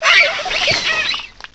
downsample cries